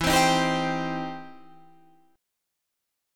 EmM7#5 chord